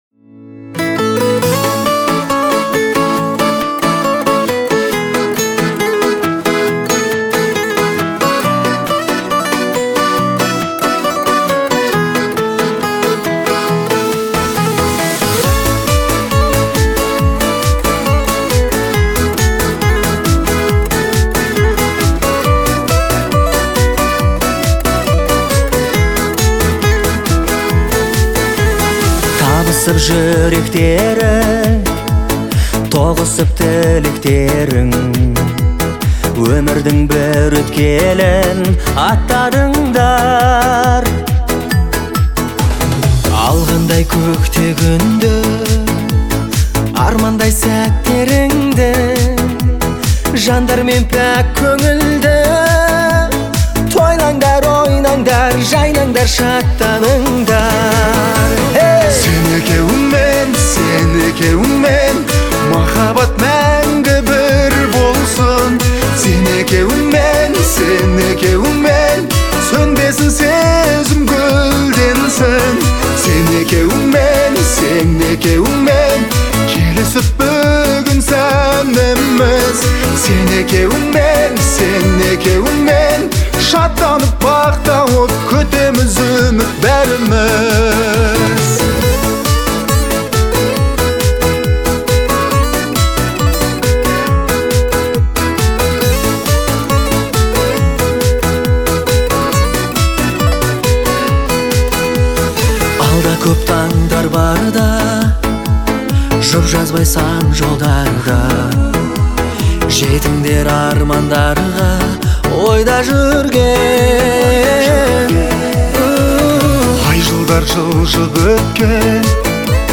романтическая баллада